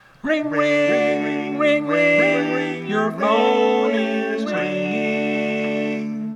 How many parts: 4
Type: Barbershop
Comments: A ringtone I created in barbershop style.
All Parts mix: